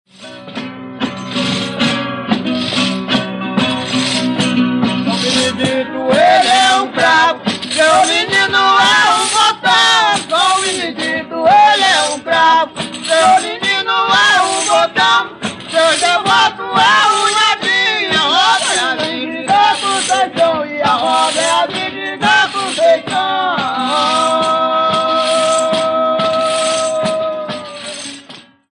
Ticumbi
Os cantos - alternados com as falas dos reis e dos embaixadores ou secretários - são entoados, em conjunto, pelos guerreiros das duas hostes, ao som de pandeiros, chocalhos e da viola que "dá o tom".